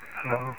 EVP's From Some Very Friendly Spirits